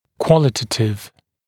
[‘kwɔlɪtətɪv][‘куолитэтив]качественный